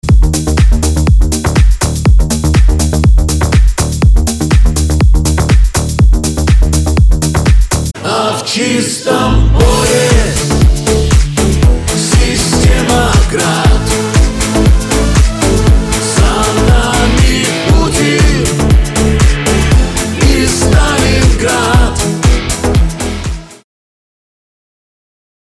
• Качество: 192, Stereo
мужской голос
патриотические
цикличные